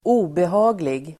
Uttal: [²'o:beha:glig]